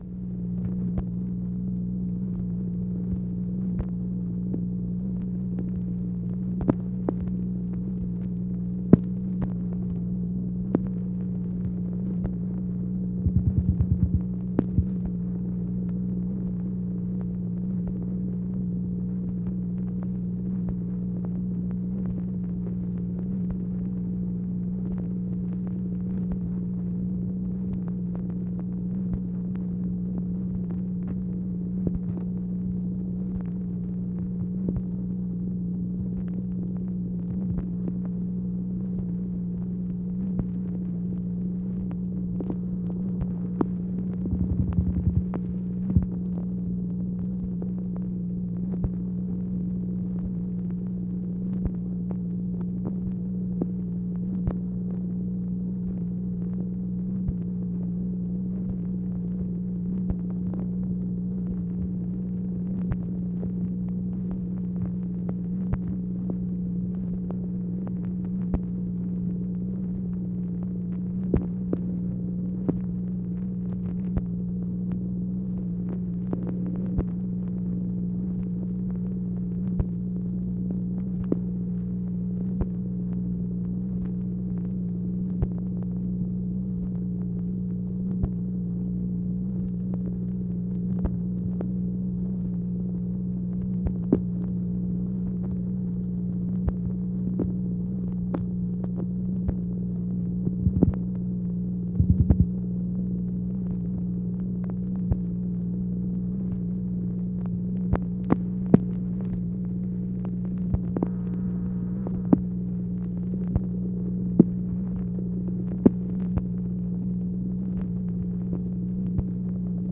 MACHINE NOISE, January 20, 1964
Secret White House Tapes